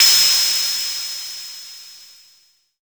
Index of /90_sSampleCDs/EdgeSounds - Drum Mashines VOL-1/CZ-DRUMS KIT
CZCRASH.wav